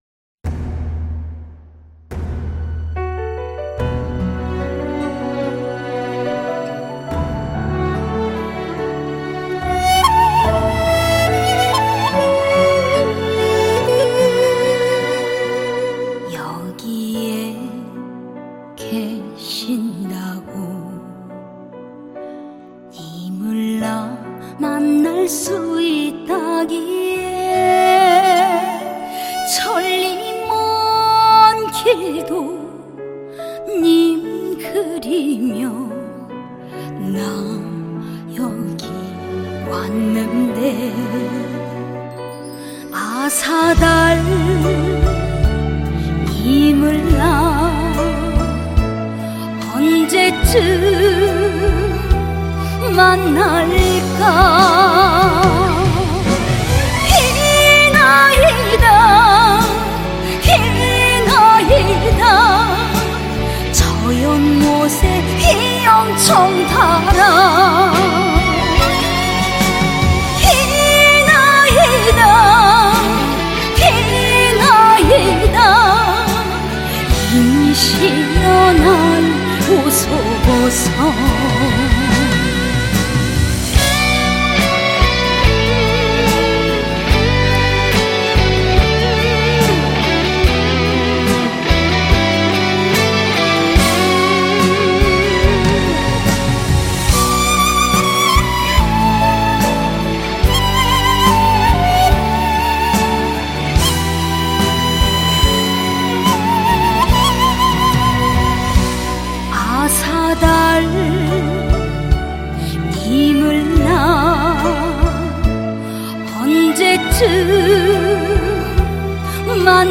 KPop Song
Label Ballad